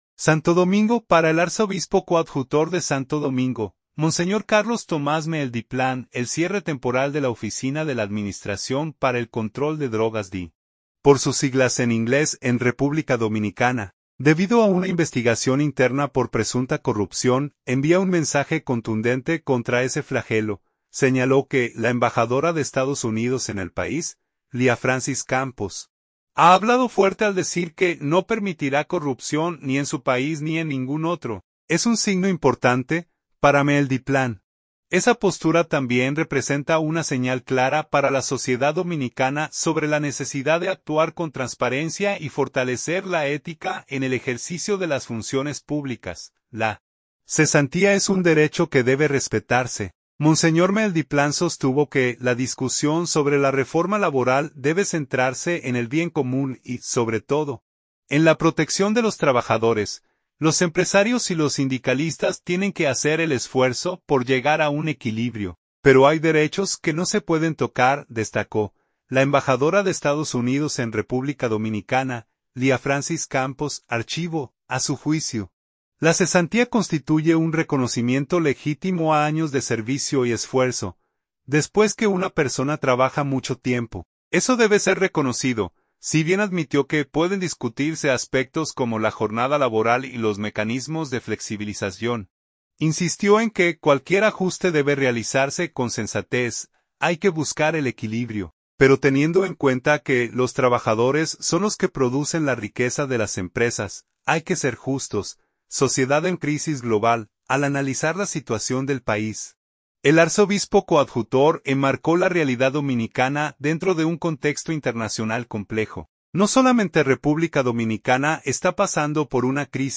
“Nos acostumbramos a un liderazgo como el de Nicolás de Jesús López Rodríguez, que asumió un papel muy activo en momentos en que el país tenía menos institucionalidad”, expresó en el Programa el Despertador